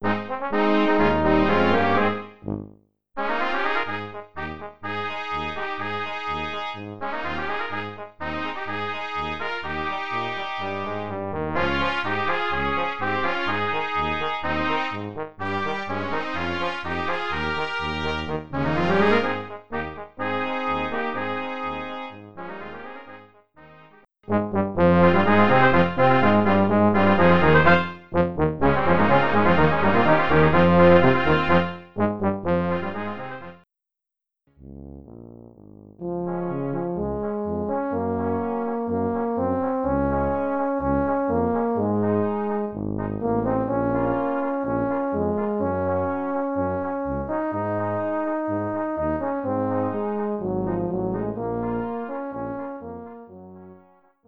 Quintett - Besetzung